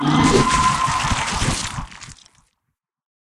mob / horse / zombie / death.ogg